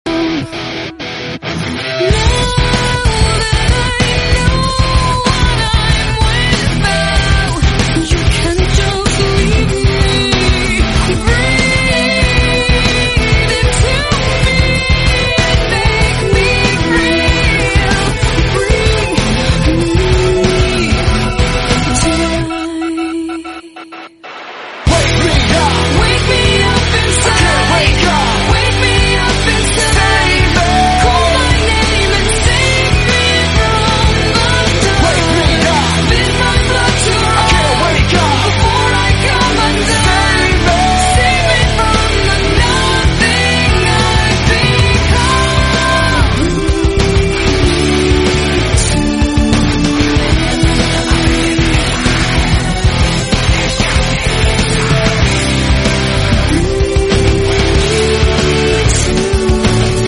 Alhamdulillah Reunion & Jamming Session